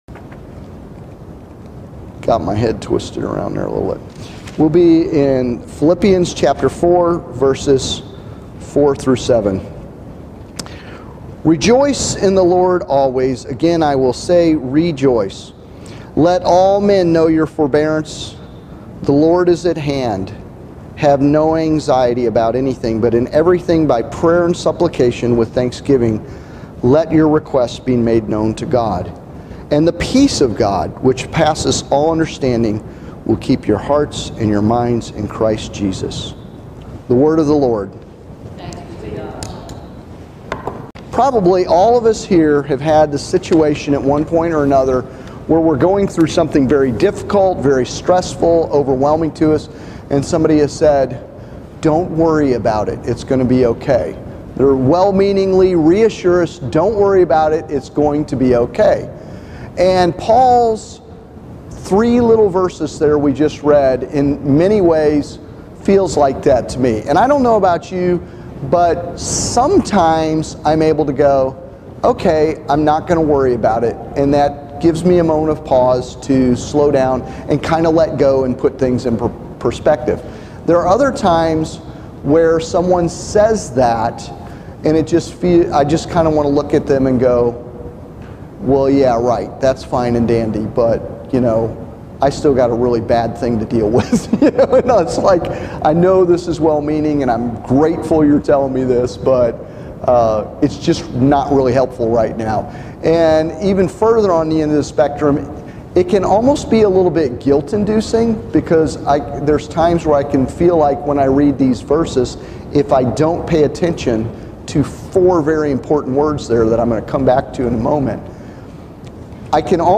The Four Words that are the Secret to Letting Go of Anxiety is a sermon based on Philippians 4:4-7.